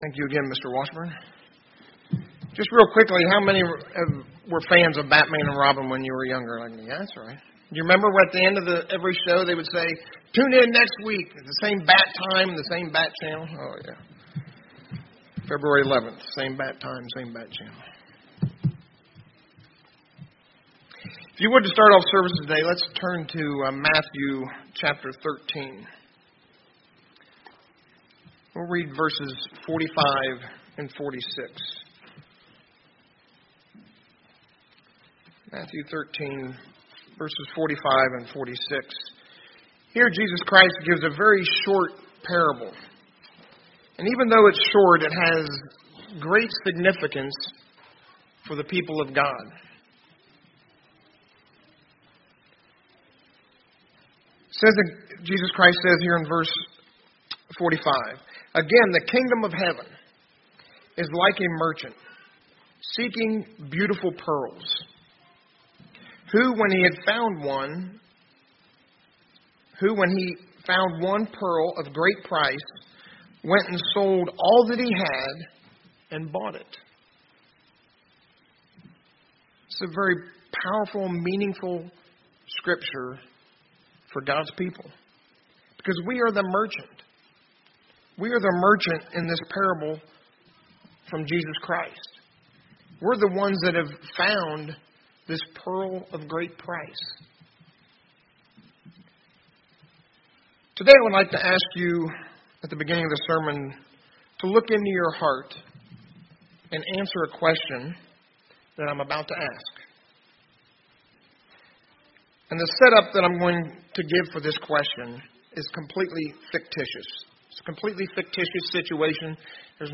Given in Indianapolis, IN